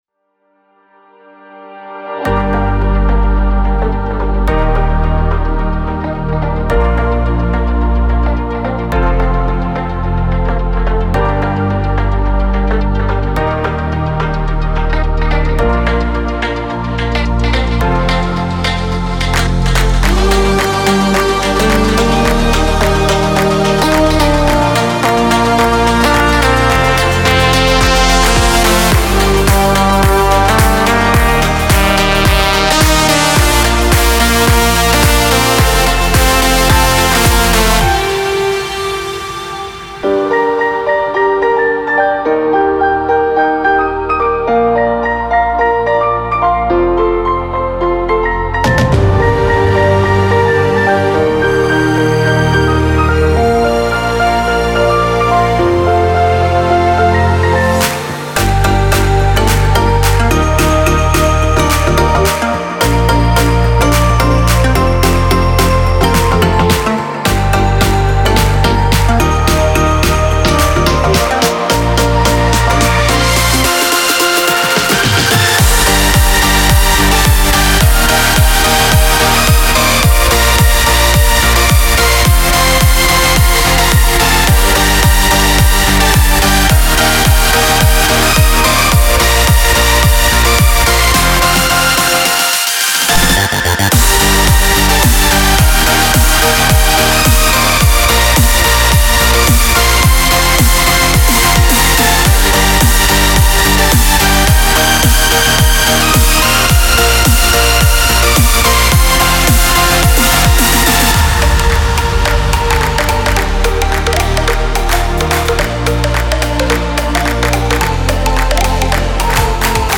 House, Happy, Hopeful, Euphoric, Energetic